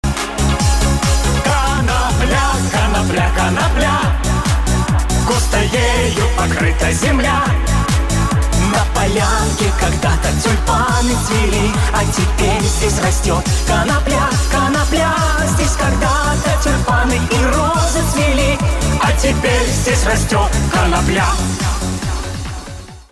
• Качество: 192, Stereo
прикольные